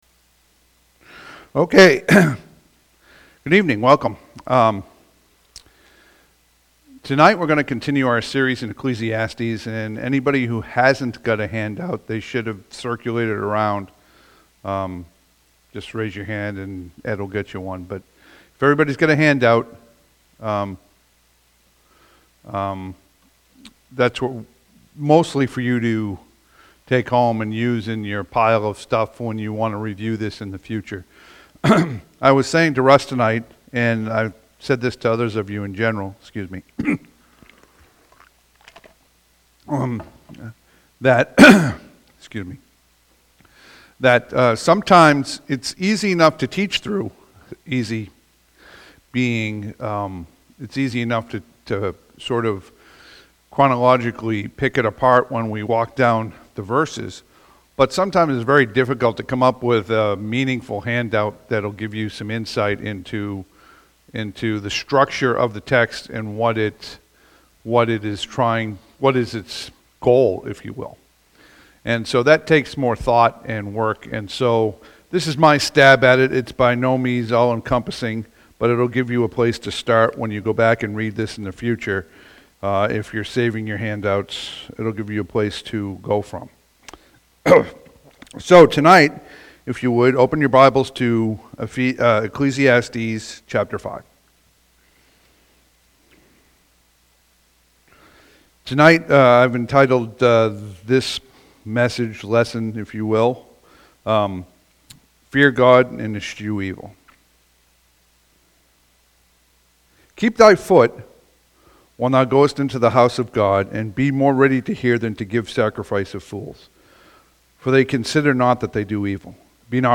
Passage: Ecclesiastes 5 Service Type: Sunday PM « June 29